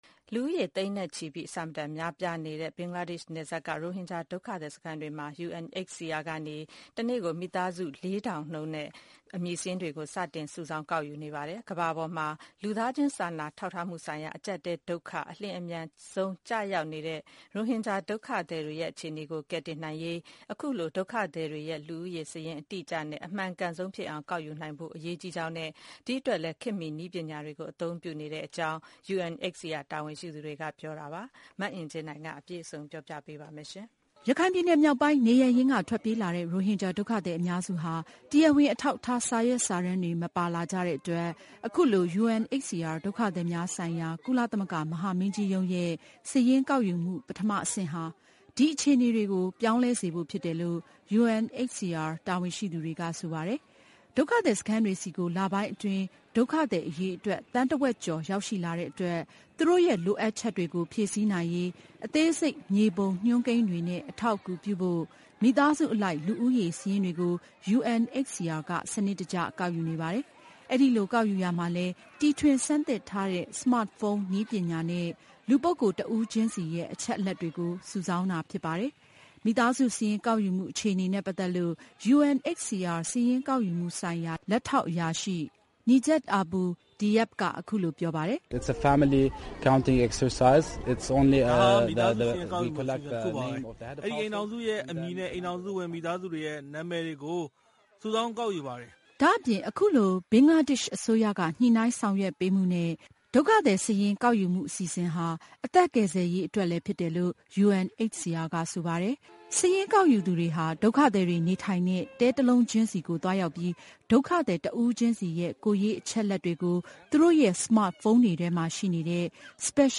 စာရင်းကောက်ယူသူတွေဟာ ဒုက္ခသည်တွေနေထိုင်တဲ့ တဲတလုံးချင်းစီကိုသွားရောက်ပြီး ဒုက္ခသည်တဦးချင်းစီရဲ့ ကိုယ်ရေးအချက်အလက်တွေကို သူတို့ရဲ့ smart ဖုန်းတွေထဲမှာရှိနေတဲ့ specialized app လို့ခေါ်တဲ့အထူးစီစဉ်ထားတဲ့ application တွေထဲမှာထည့်သွင်းပြီးမိသားစုဝင်တဦးချင်းစီရဲ့ ဓါတ်ပုံတွေနဲ့အတူ ကောက်ယူစုဆောင်းတာပါ။ အဲဒီနောက် အမည်စာရင်းရှိသွားတဲ့ မိသားစုတစုချင်းစီကို အဝါရောက်ကဒ်ပြားတခုစီ ထုတ်ပေးပါတယ်။ ဒီကဒ်ပြားရရှိထားတဲ့ ရိုဟင်ဂျာဒုက္ခသည် မိသားစုထဲက လင်ယောက်ျားဖြစ်သူနဲ့ ဇနီးဖြစ်သူတို့က အခုလိုပြောပြကြပါတယ်။